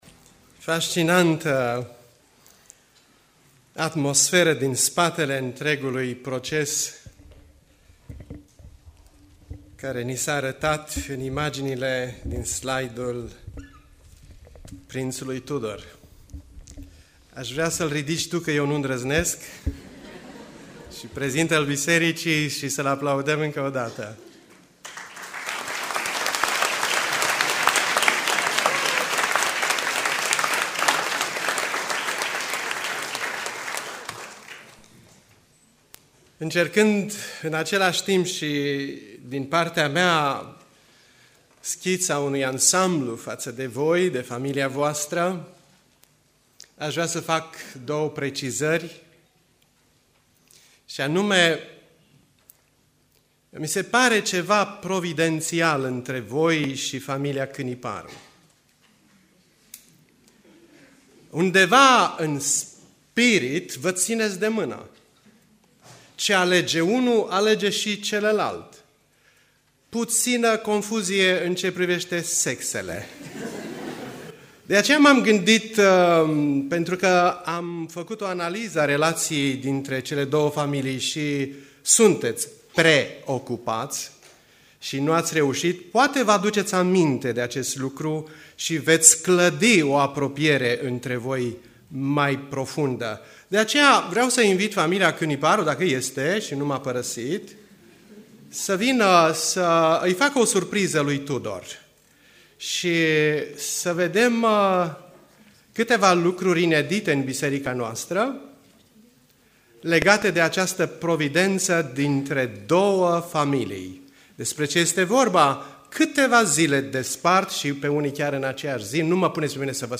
Predica Aplicatie - Ieremia cap. 16